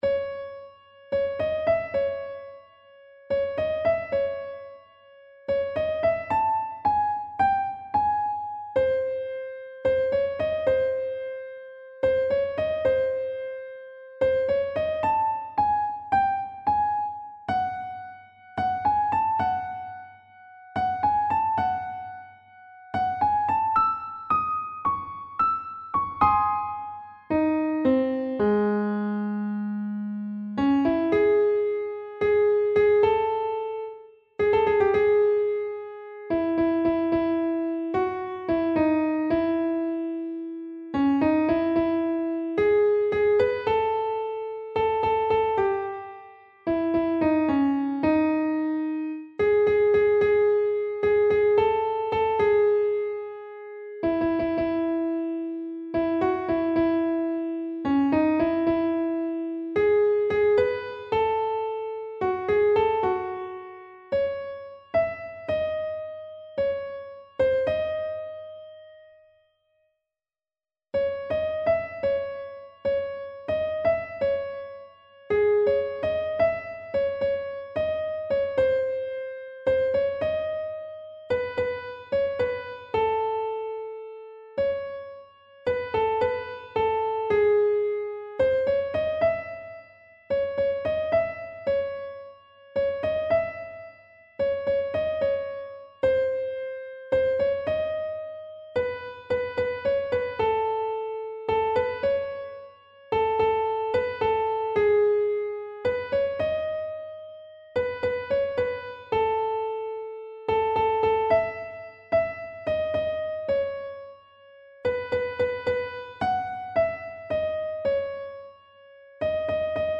نت کیبورد